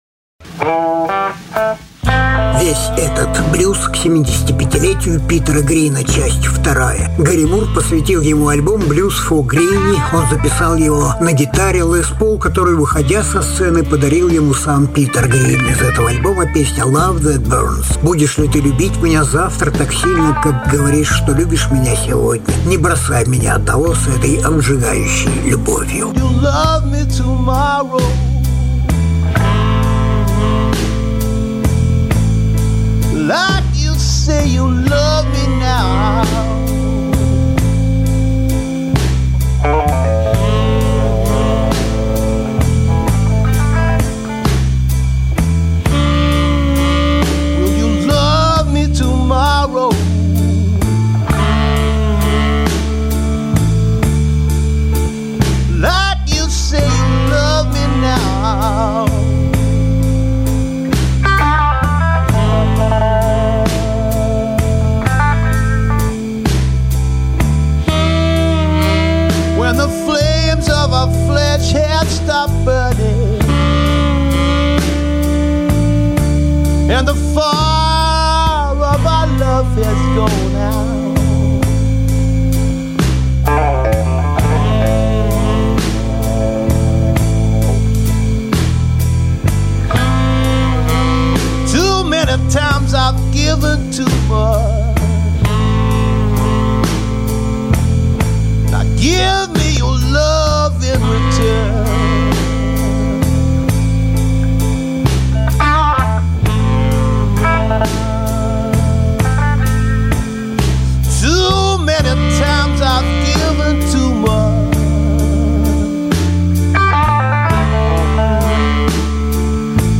британский блюз-роковый гитарист
Жанр: Блюз